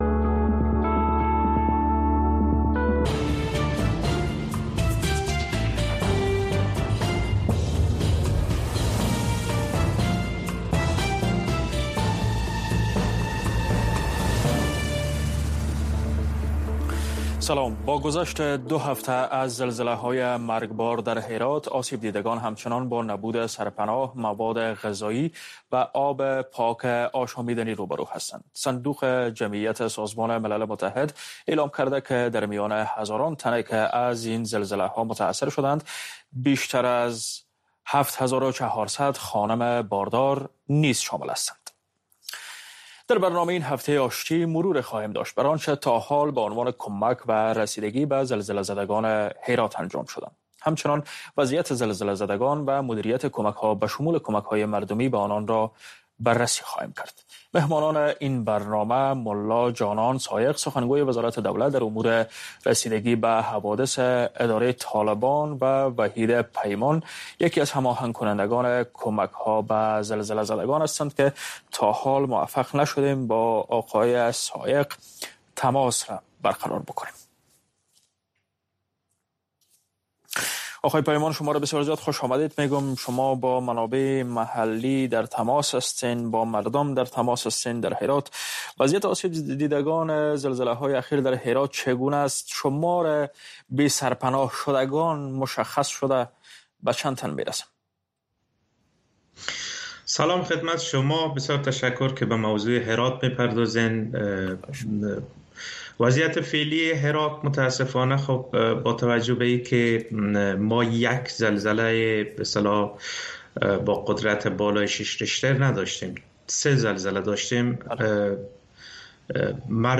د وی او اې ډيوه راډيو ماښامنۍ خبرونه چالان کړئ اؤ د ورځې د مهمو تازه خبرونو سرليکونه واورئ.